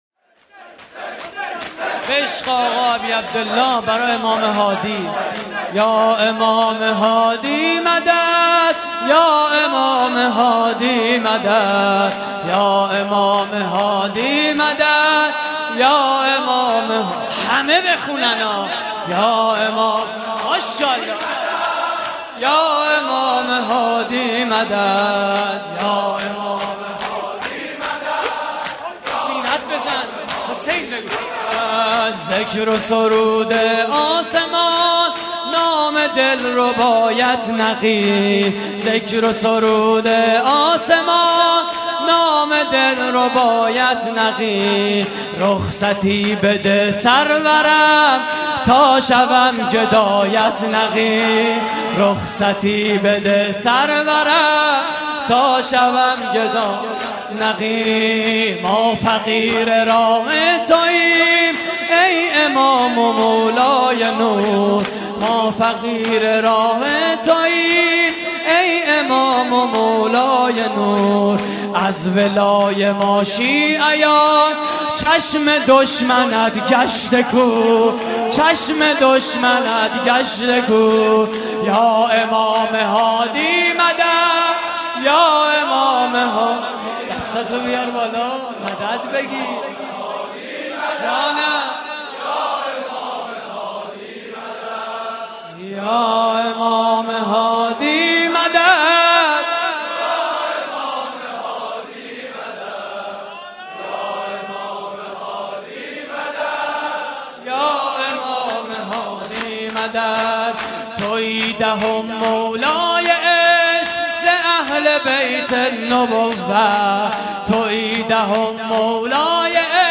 shoor-shahadat-Imam-Hadi.mp3